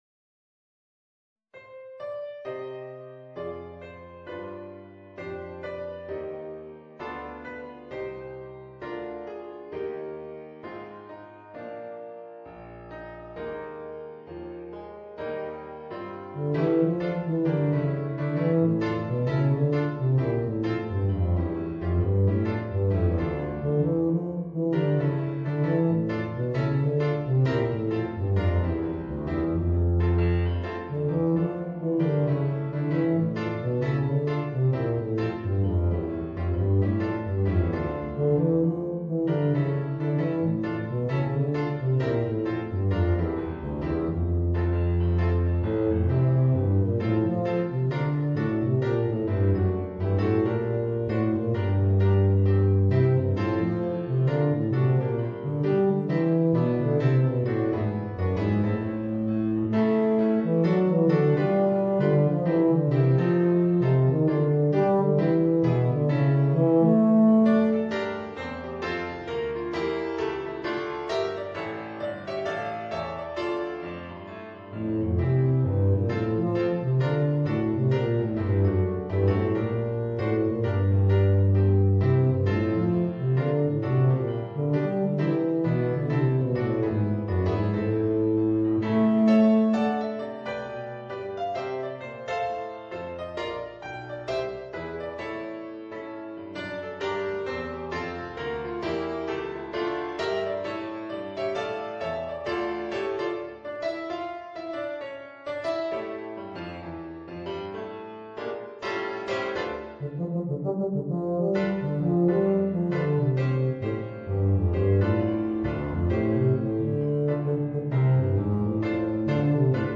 Voicing: Eb Bass and Piano